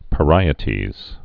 (pə-rīĭ-tēz)